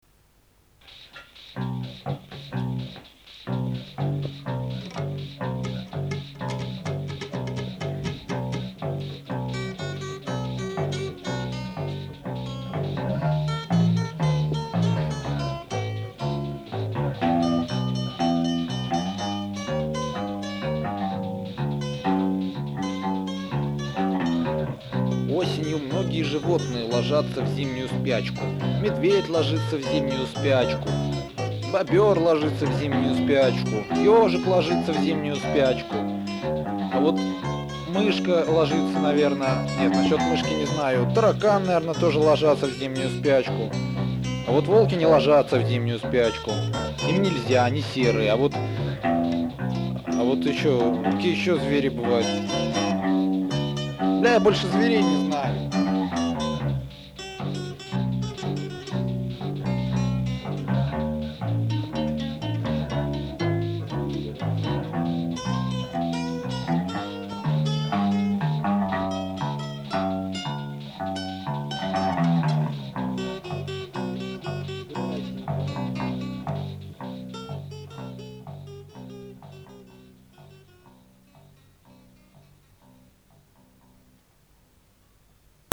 спонтанно записали в домашних условиях
бас, барабаны
вокал, гитара, тексты